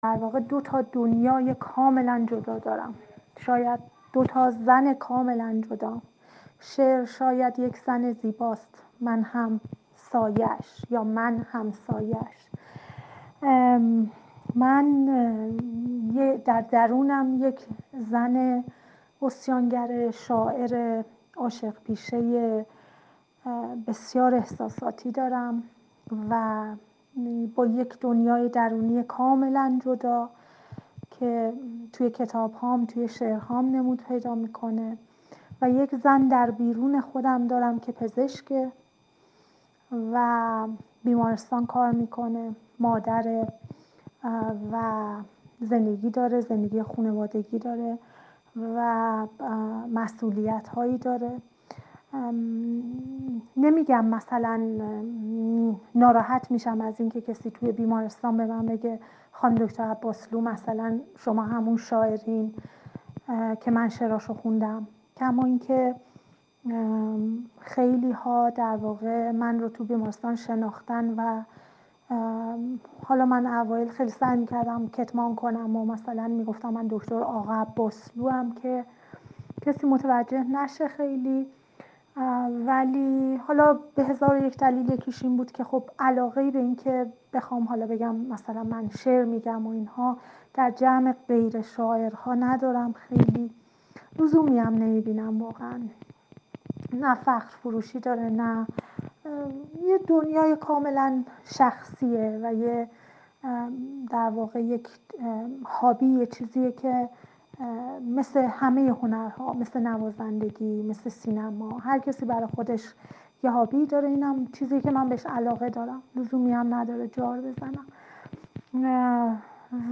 در گفت‌وگو با ایکنا:
شاعر و پزشک